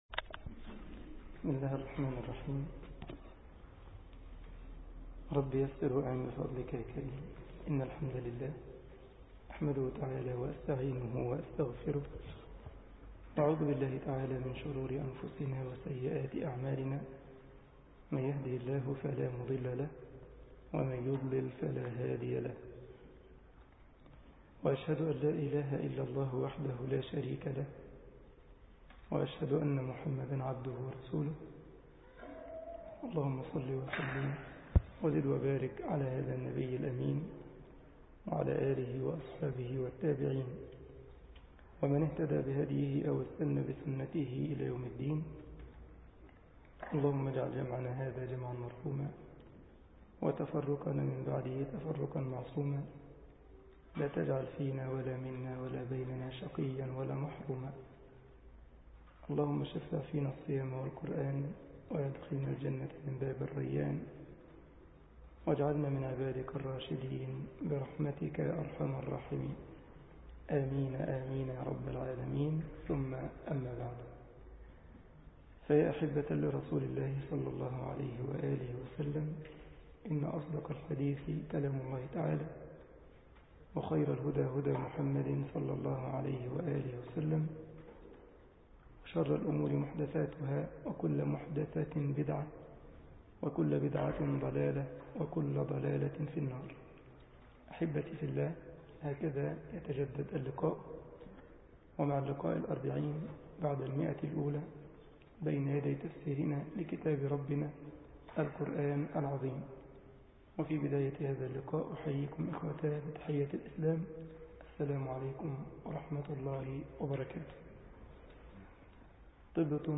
مسجد الجمعية الإسلامية بالسارلند ـ ألمانيا درس 09 رمضان 1433هـ